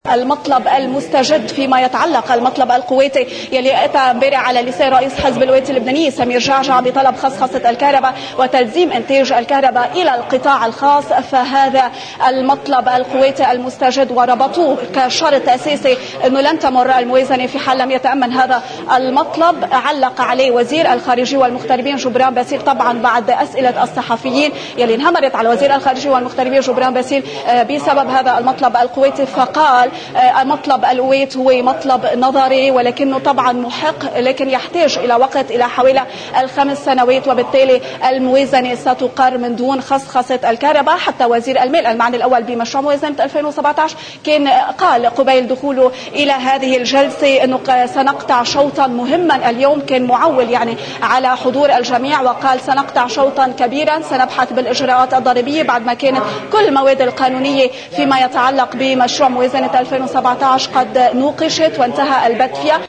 علّق وزير الخارجية جبران باسيل، رداً على أسئلة الصحفيين المنهمرة لأخذ جواب بهذا الخصوص، فقال: